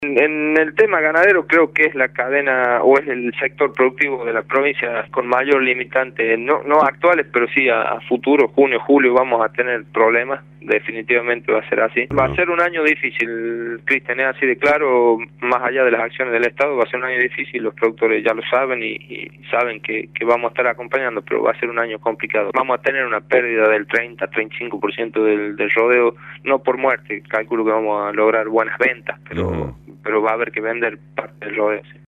Jorge Salomón, secretario de Ganadería, por Radio La Red